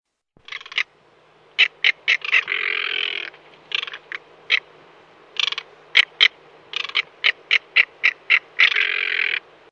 caracará.
Todos sons do centro da cidade de Ubatuba.